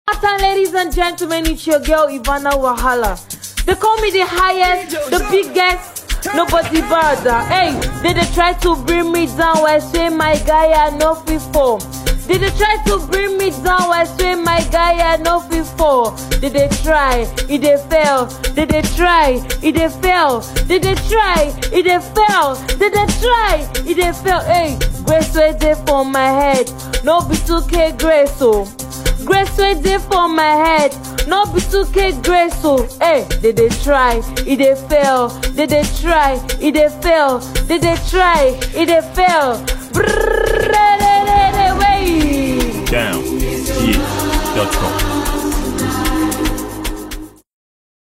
is a tiktok trending song released by Nigeria’s vocalist